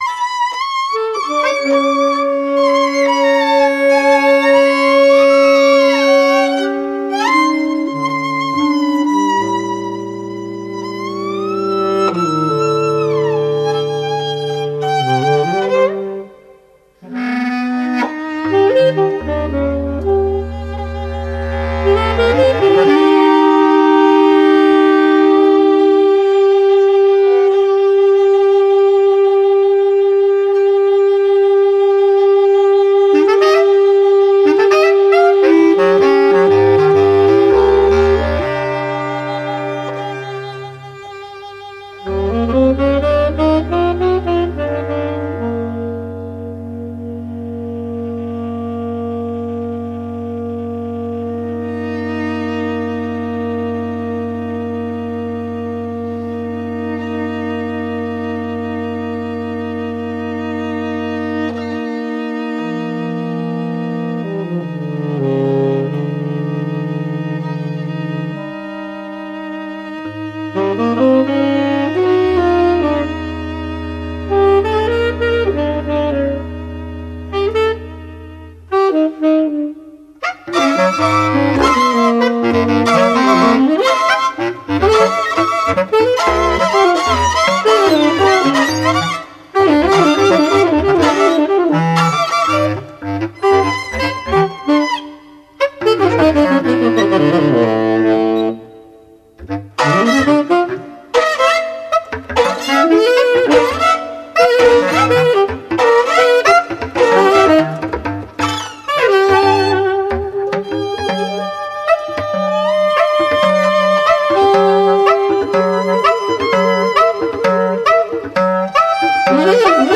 Bariton-, Tenor-, Sopransaxophon
Altsaxophon, Bassklarinette
Kontrabaß, Orgel
Violine